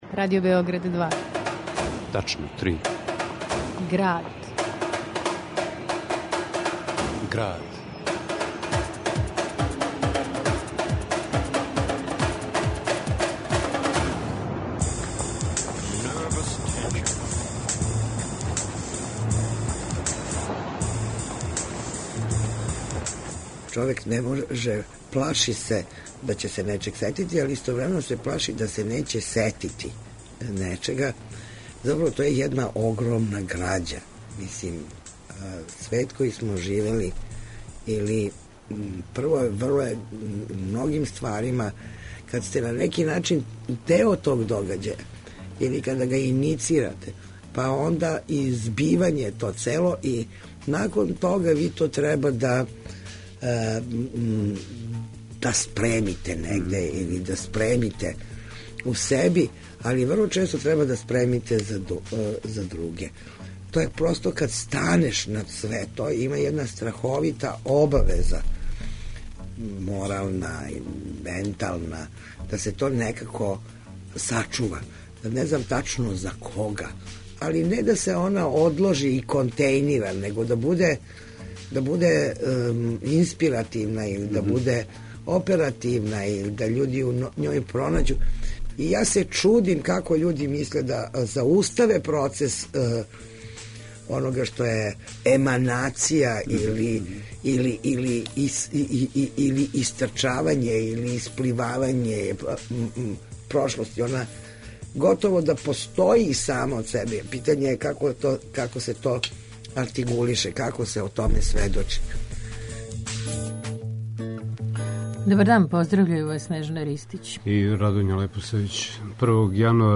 У Граду, Боркине траке: снимци њених гостовања...